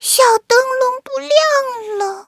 文件 文件历史 文件用途 全域文件用途 Chorong_die.ogg （Ogg Vorbis声音文件，长度2.3秒，107 kbps，文件大小：30 KB） 源地址:地下城与勇士游戏语音 文件历史 点击某个日期/时间查看对应时刻的文件。